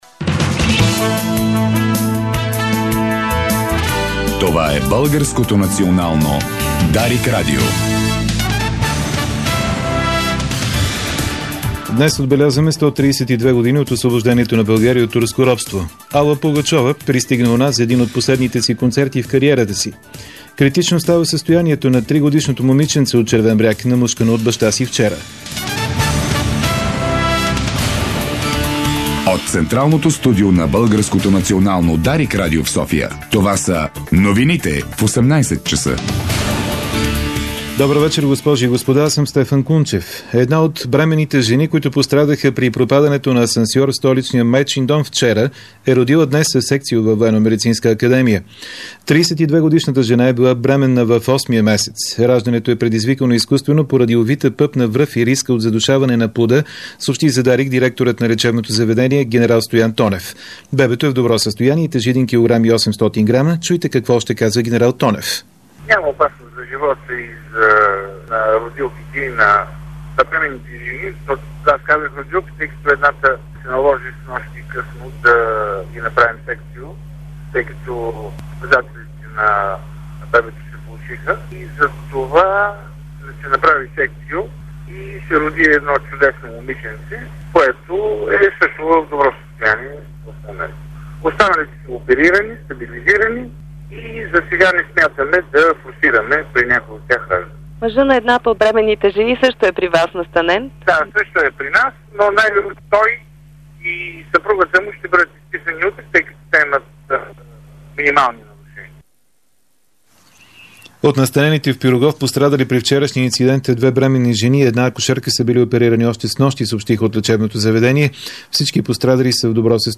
Обзорна информационна емисия - 03.03.2010